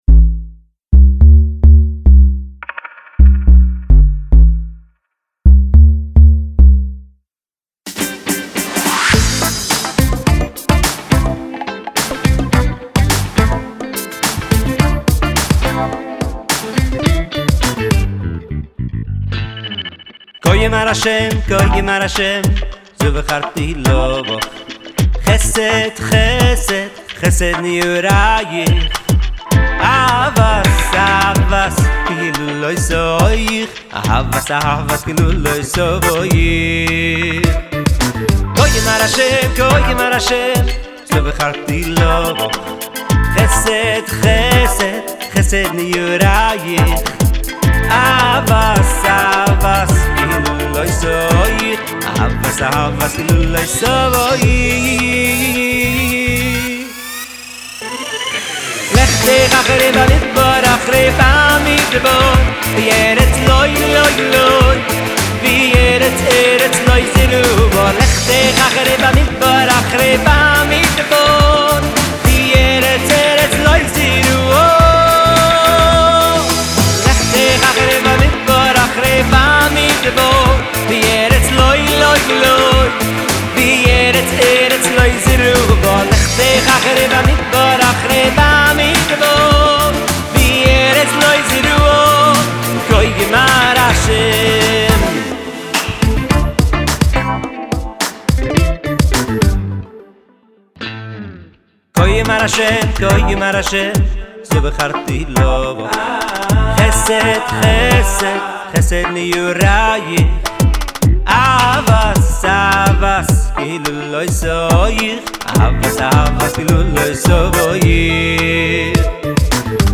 שירים חסידיים